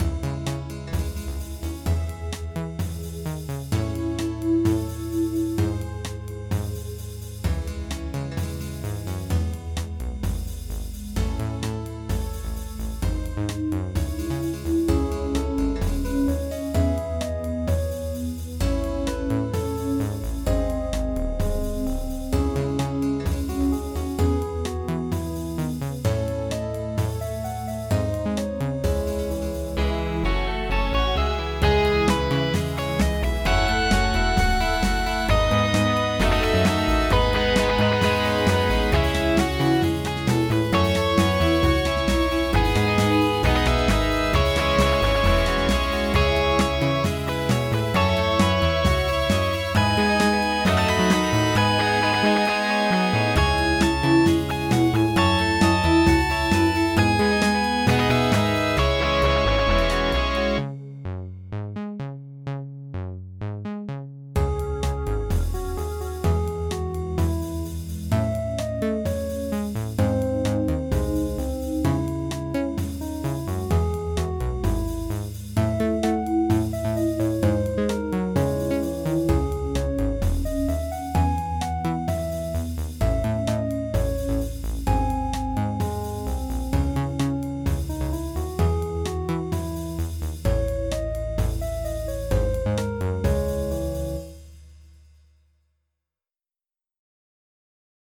After the double chorus, we are going to add a single measure with only bass.